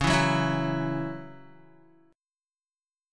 C#mbb5 chord